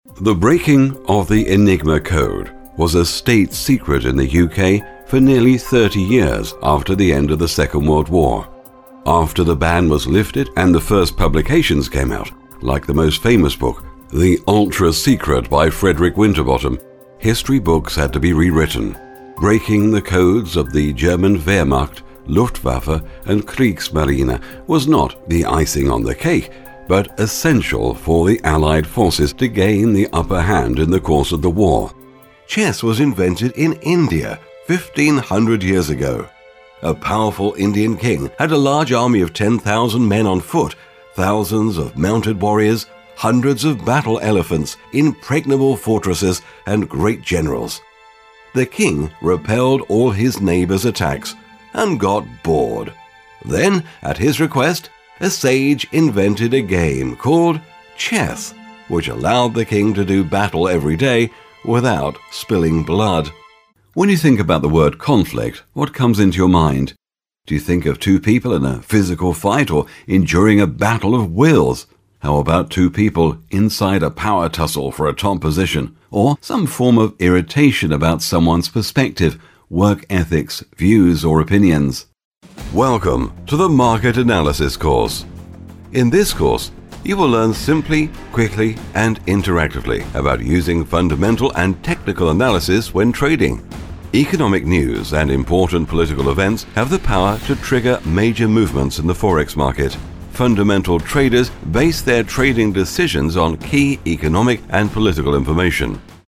Sprechprobe: Sonstiges (Muttersprache):
About me: I am a professional voiceover with a rich but clear, resonant, authorative voice. I have a standard English accent, but am very flexible in styles. I have my own broadcast quality studio with a Neumann U87 & TLM 103 microphones and offer fast turnarounds on recording.